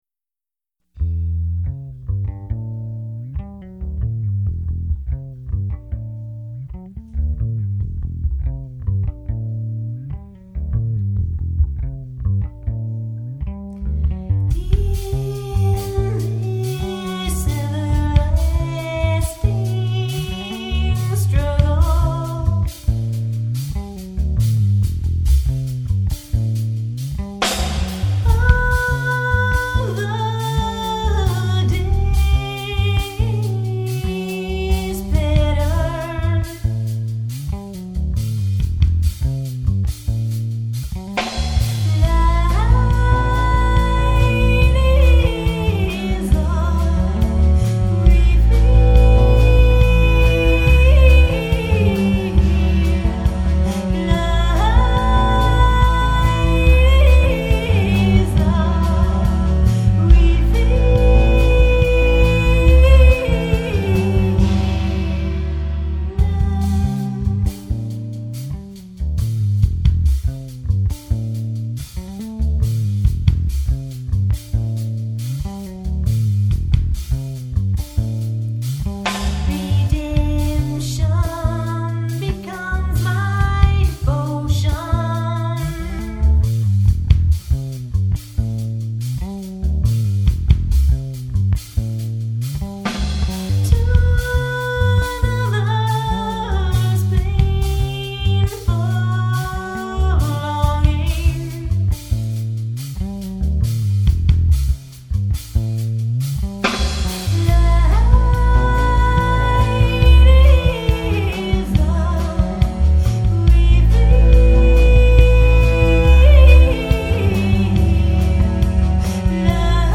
Sometimes it’s noisy. Sometimes distorted.